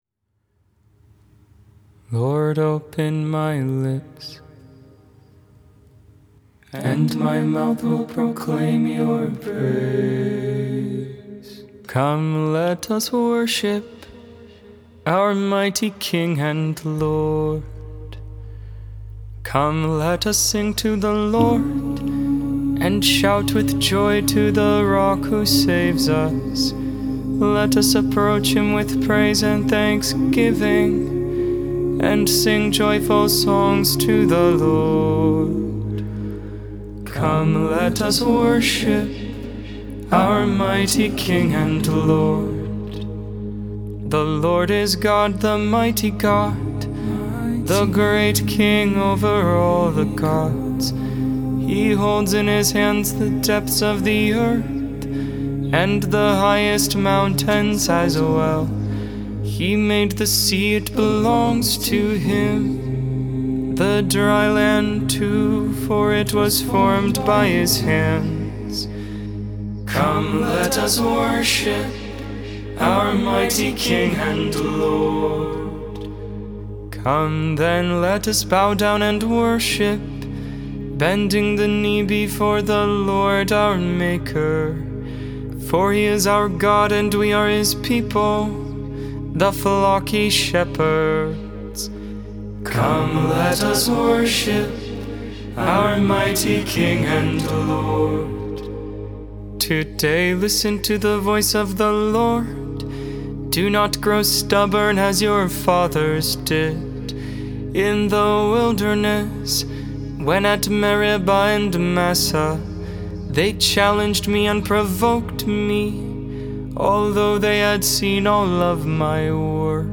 Gregorian tone 1
Gregorian tone 4